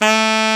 Index of /90_sSampleCDs/Roland L-CD702/VOL-2/SAX_Alto Short/SAX_A.ff 414 Sh
SAX A.FF A03.wav